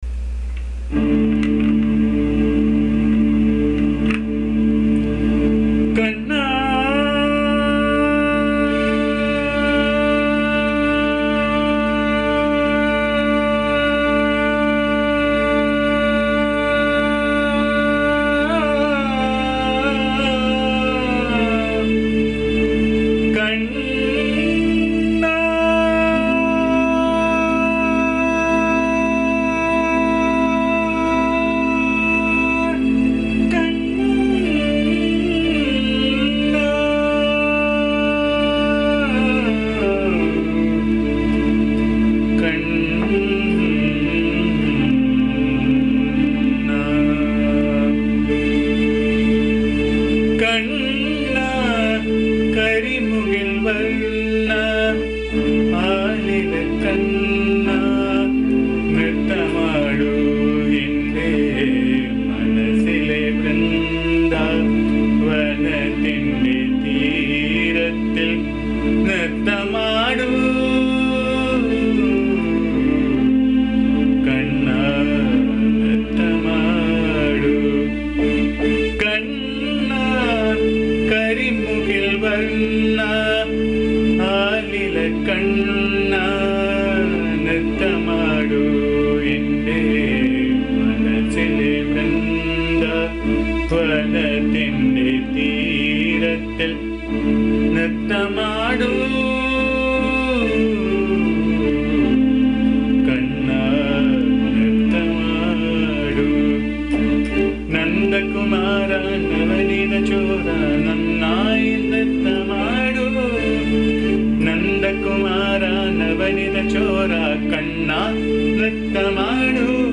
This is a very beautiful song set in Raga Madhyamaavathi.
The song sung in my voice can be found here.
bhajan song